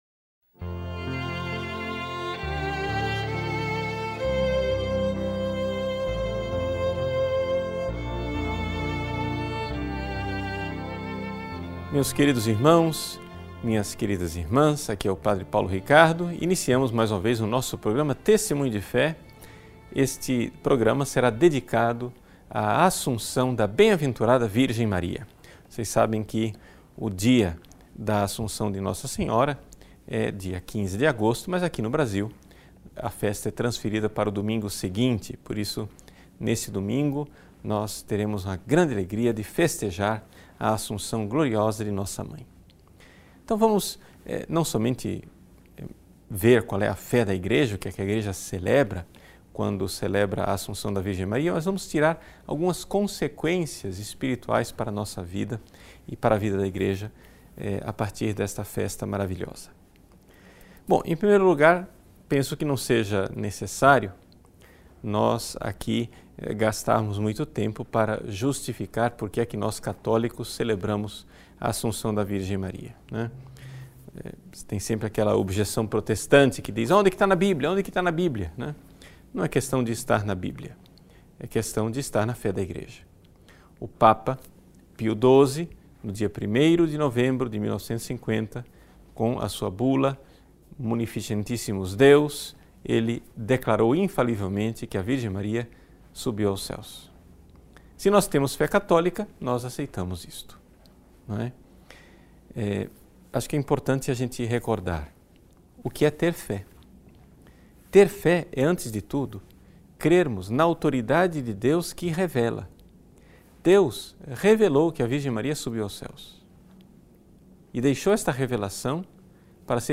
Nesta meditação